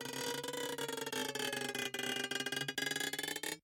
ziplineFull.ogg